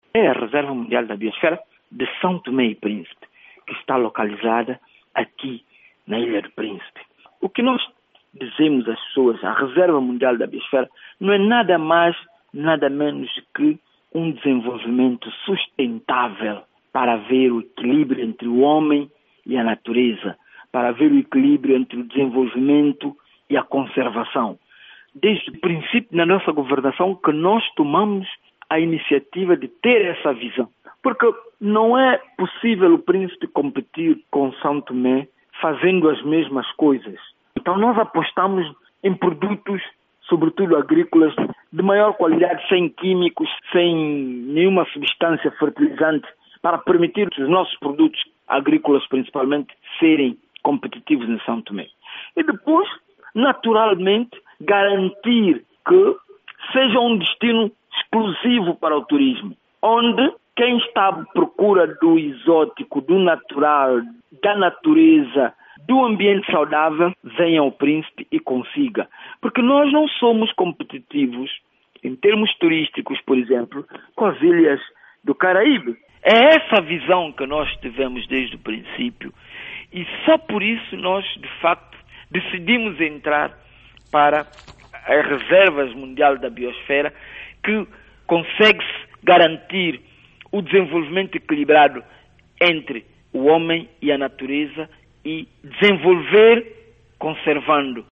Entrevista de Tozé Cassandra - 1:38